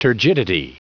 Prononciation du mot turgidity en anglais (fichier audio)
Prononciation du mot : turgidity